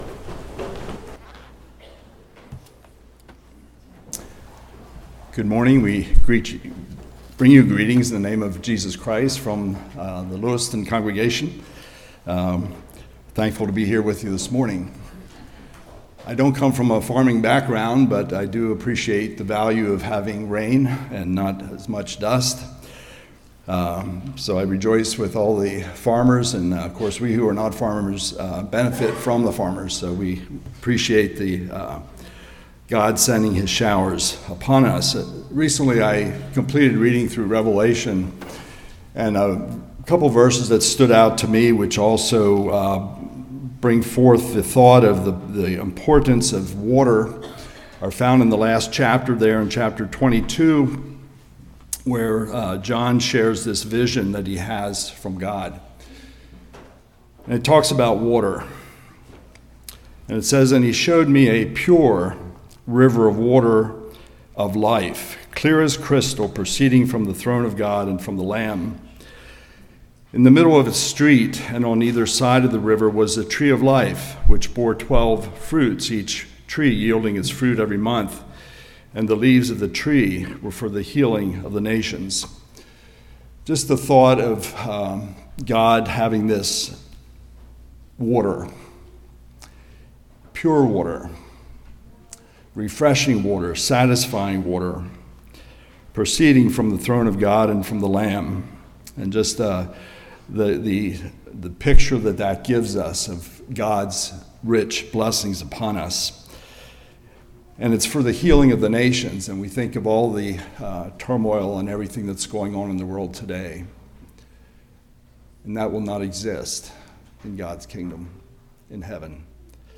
1 Peter 5:1-7 Service Type: Morning Does Jesus care about our burdens?